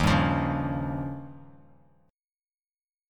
Listen to D#9 strummed